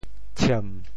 “僭”字用潮州话怎么说？
僭 部首拼音 部首 亻 总笔划 14 部外笔划 12 普通话 jiàn 潮州发音 潮州 ciem3 文 中文解释 僭 <形> 虚伪 [sham;hypocritical] 僭而无征。
tshiem3.mp3